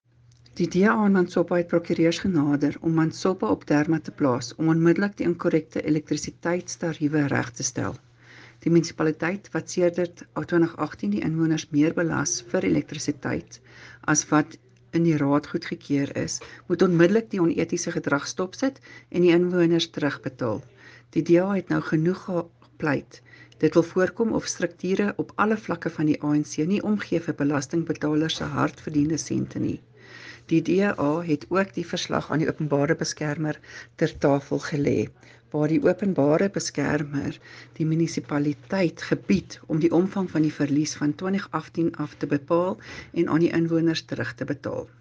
Afrikaans soundbites by Cllr Tania Halse
Afr-voicenote-2.mp3